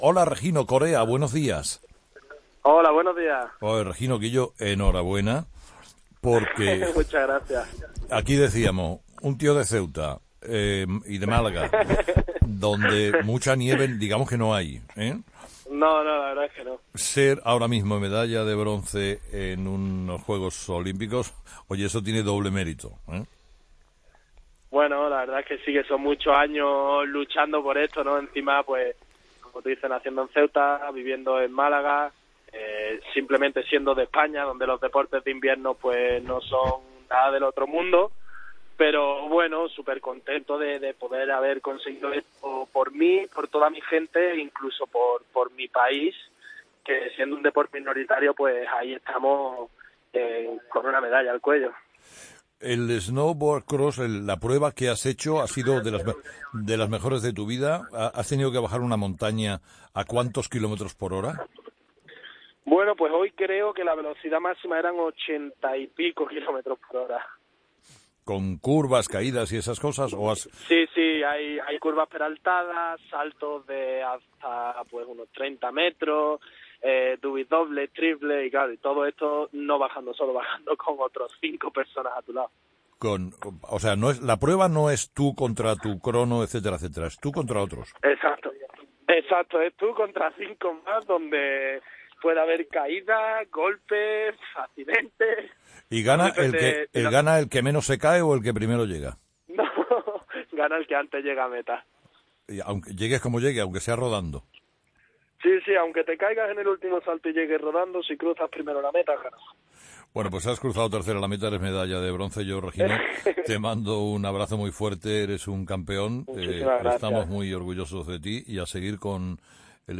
Entrevista a Regino Hernández
El deportista español, Regino Hernández, se cuelga la Medalla de bronce en la categoría de snowboard cross en Pyeongchang y entra en 'Herrera en COPE' para contar cómo se siente: "Estoy supercontento por mi, por los míos y por mi país".